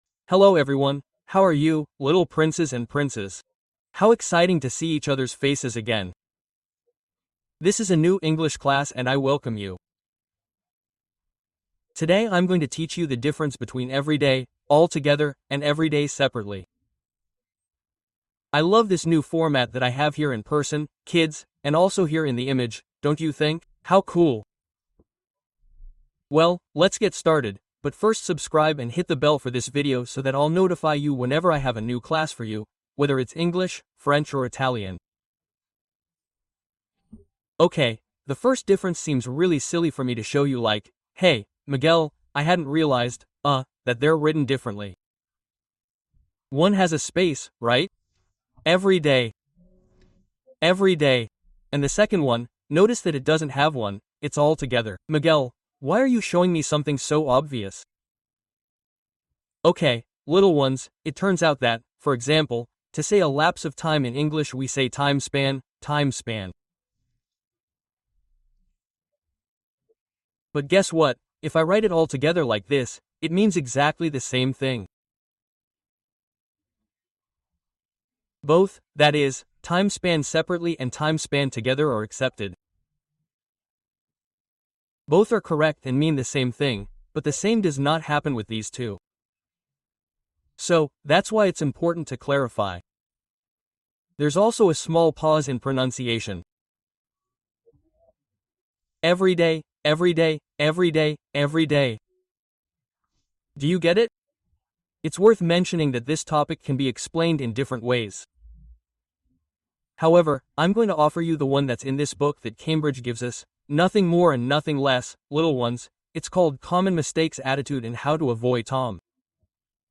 Cien adjetivos comunes del inglés con pronunciación pausada